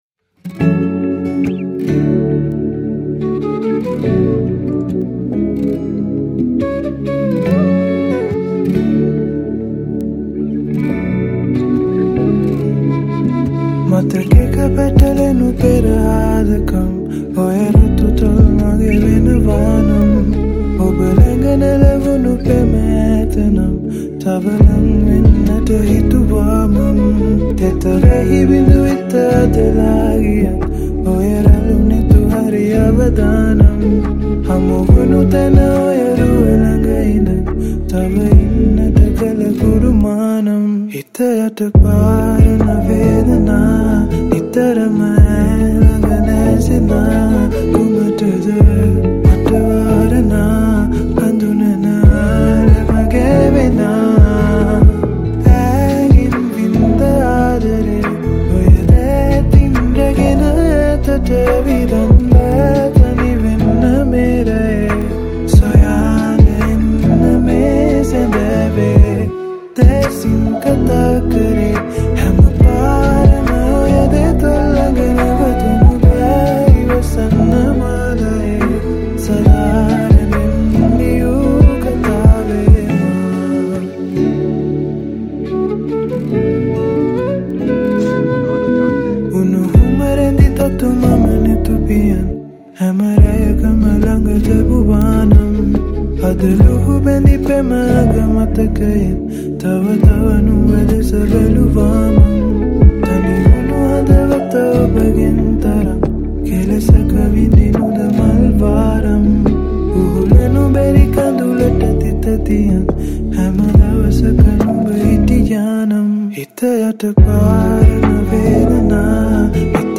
Guitars
Flute